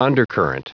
Prononciation du mot undercurrent en anglais (fichier audio)
Prononciation du mot : undercurrent